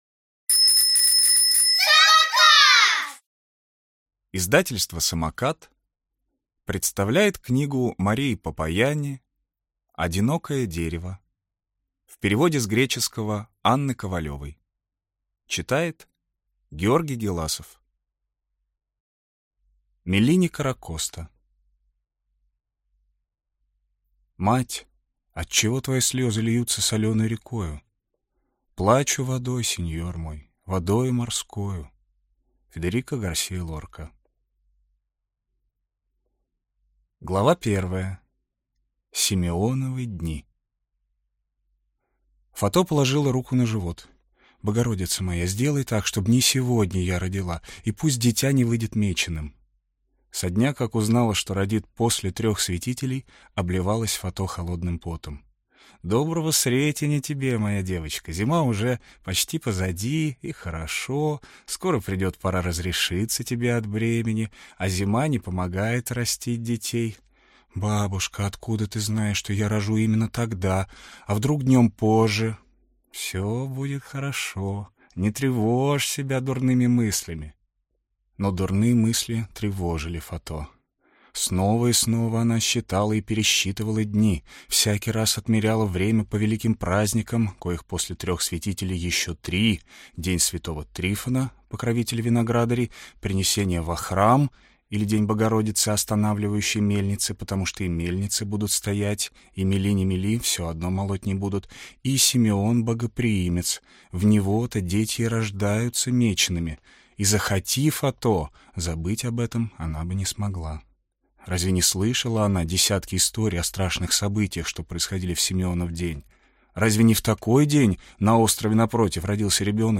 Аудиокнига Одинокое дерево | Библиотека аудиокниг